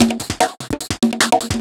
Percussion 18.wav